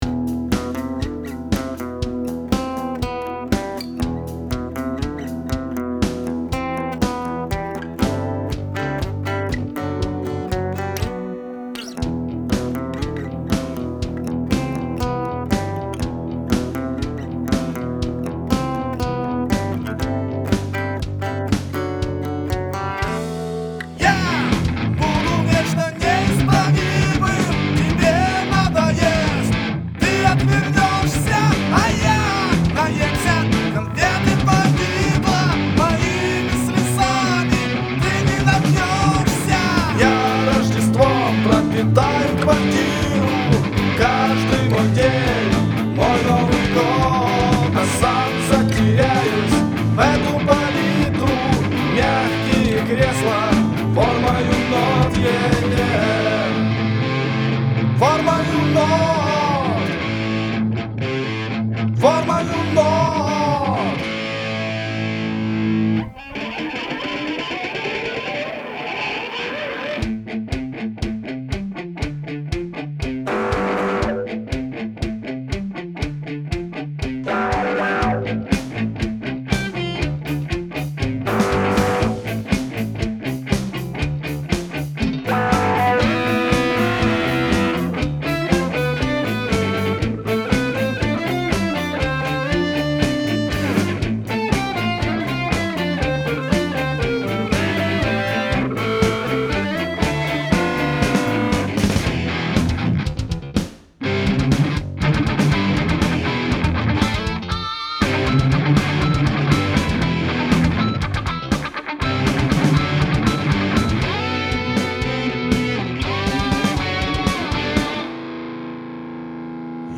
DEMO
В моем доме (Rock